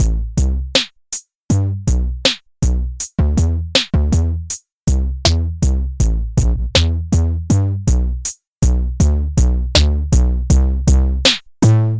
硬核振动节拍
描述：硬核氛围的节拍
标签： 80 bpm Electronic Loops Drum Loops 2.02 MB wav Key : E
声道立体声